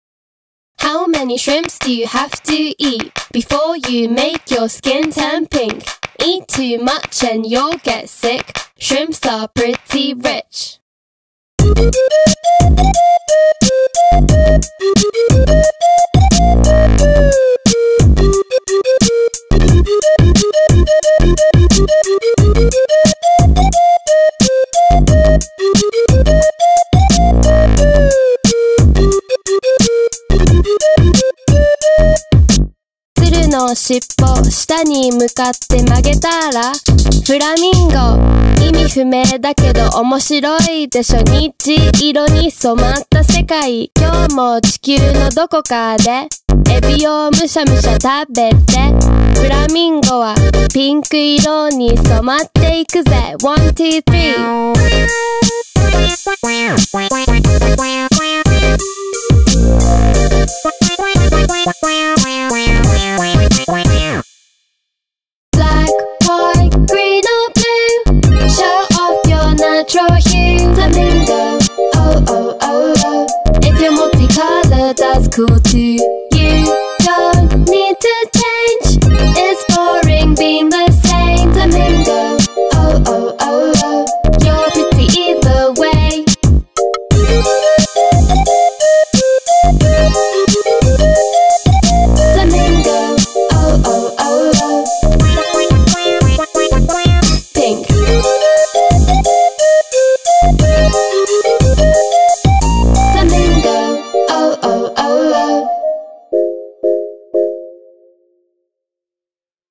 BPM89
Audio QualityPerfect (High Quality)
Comentarios[INDIE POP]